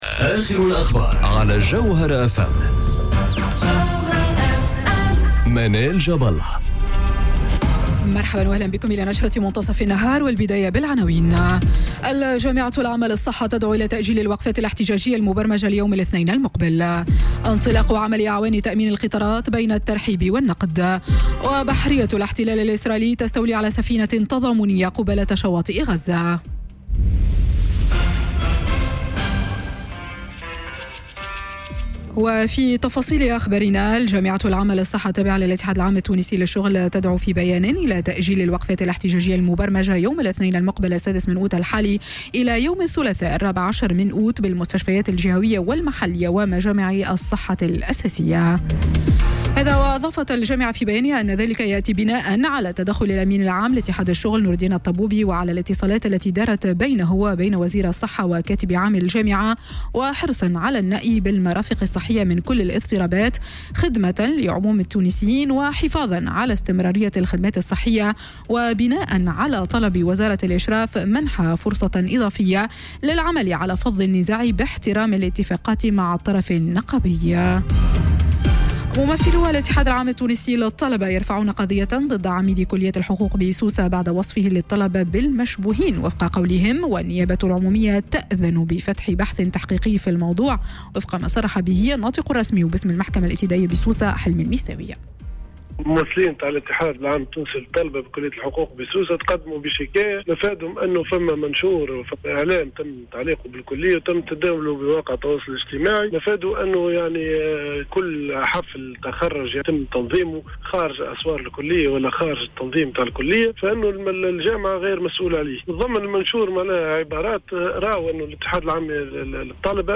نشرة أخبار منتصف النهار ليوم السبت 4 أوت 2018